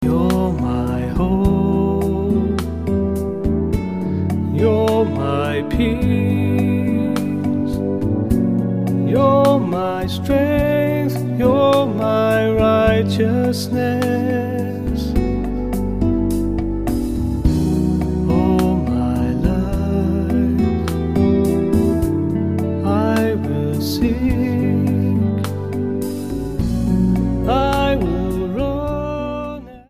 STYLE: Pop
just drums, keyboards and guitar
rich baritone voice